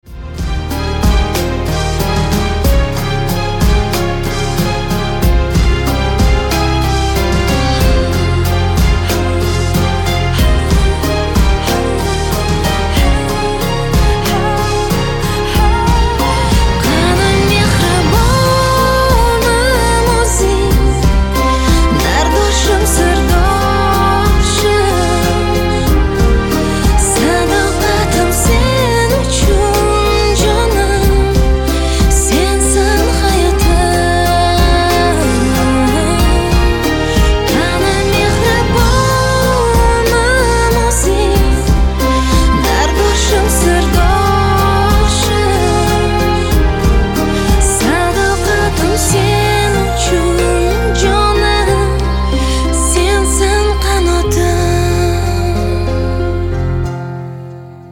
• Качество: 256, Stereo
узбекские